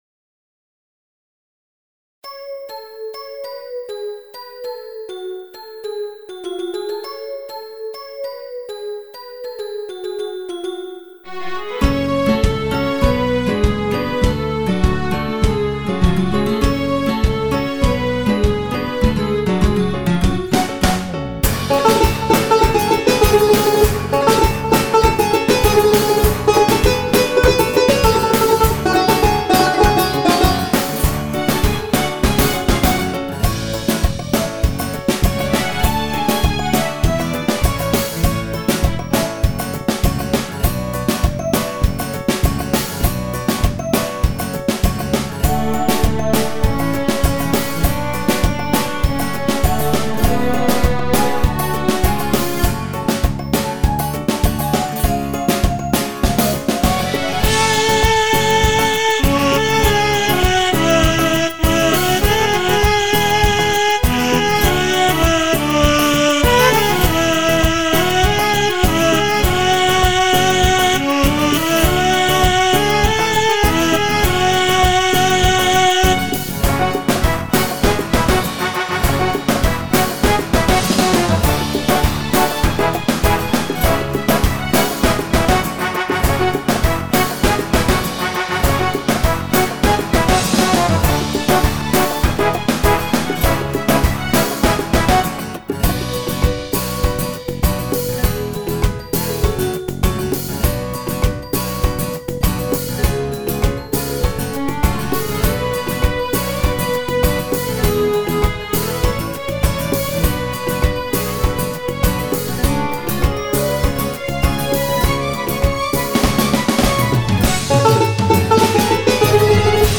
بچه-های-ایران-بی-کلام.mp3